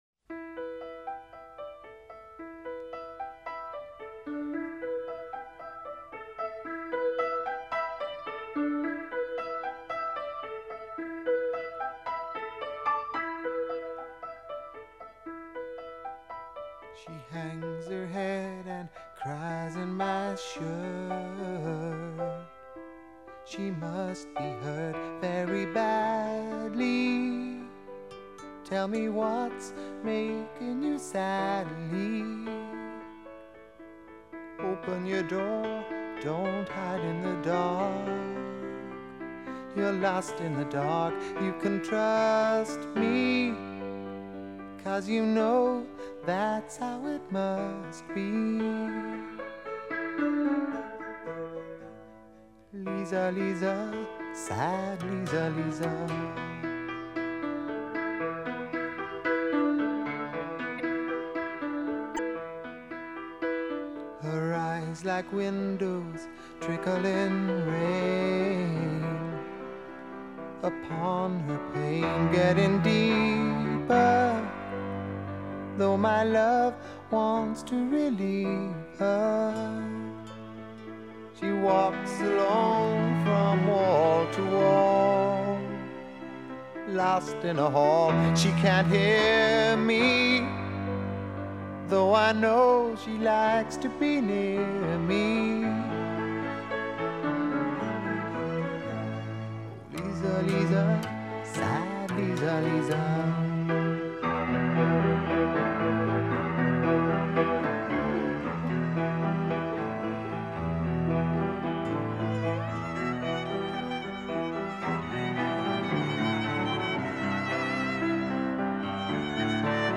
Capo 7th Fret - 1/2 - 4/4 Time